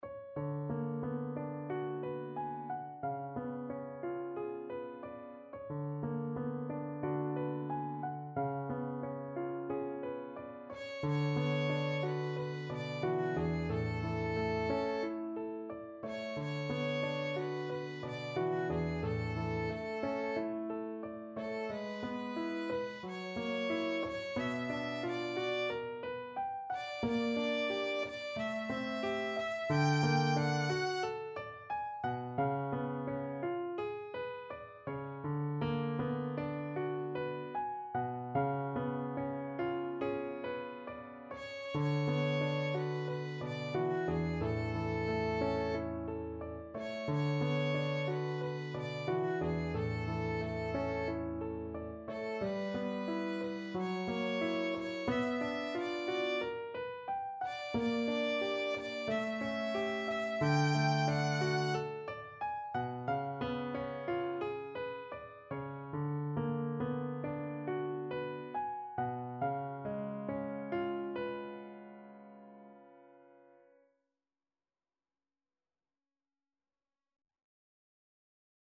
Classical Schumann, Robert Im wunderschonen Monat Mai, No. 1 from Dichterliebe, Op.48 Violin version
A major (Sounding Pitch) (View more A major Music for Violin )
2/4 (View more 2/4 Music)
~ = 45 Langsam, zart
Violin  (View more Easy Violin Music)
Classical (View more Classical Violin Music)